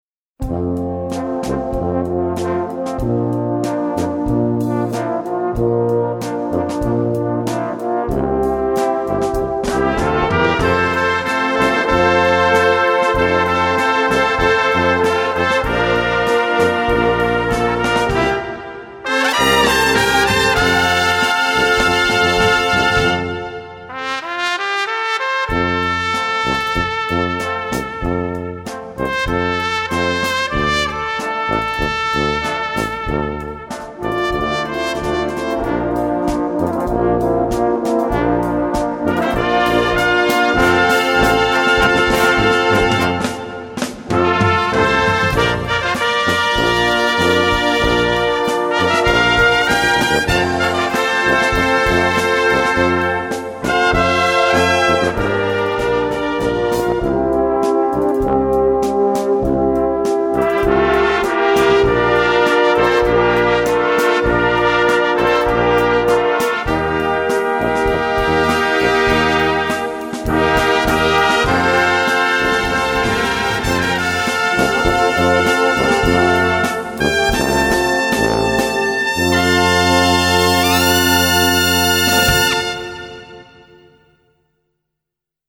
Gattung: Slowrock
Besetzung: Blasorchester